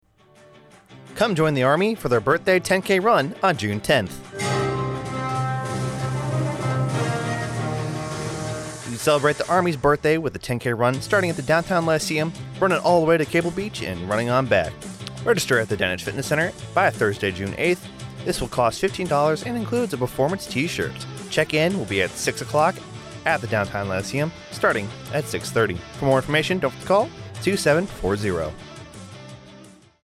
A radio spot informing listeners on NAS Guantanamo Bay of the 10k run to celebrate the Army's birthday.